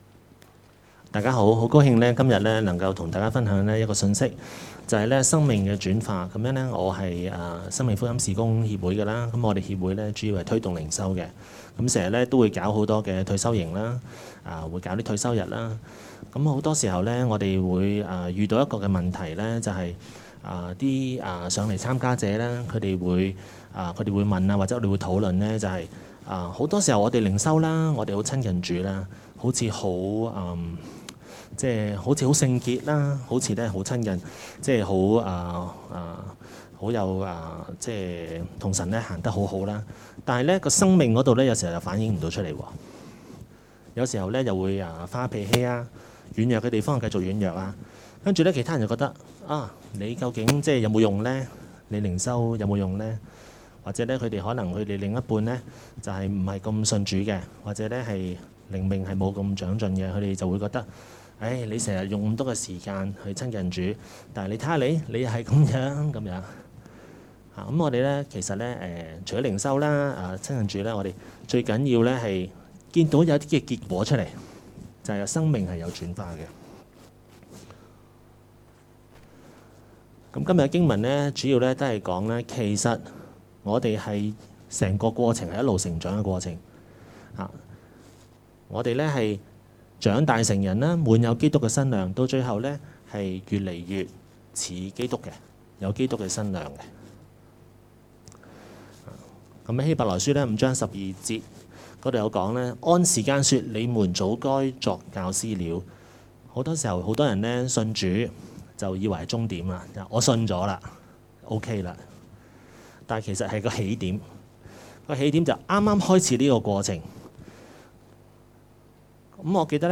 2020年7月11及12日講道